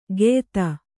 ♪ geyta